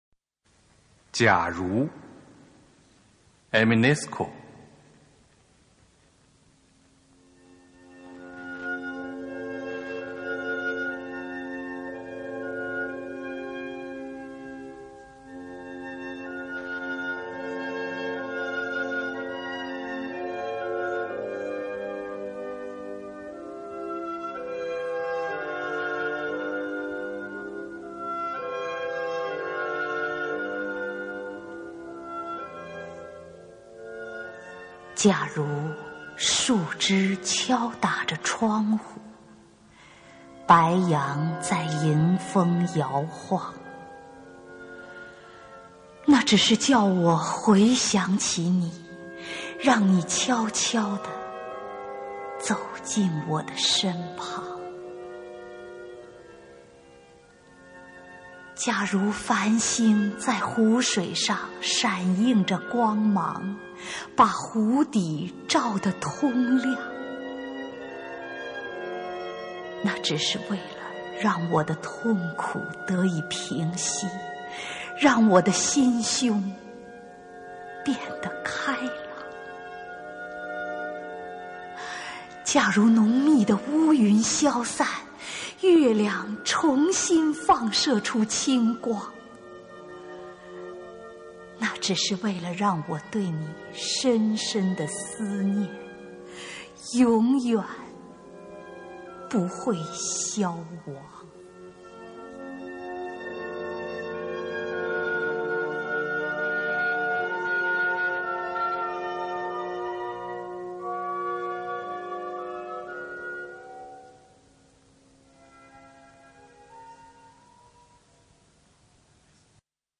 首页 视听 经典朗诵欣赏 丁建华、乔榛：外国爱情诗配乐朗诵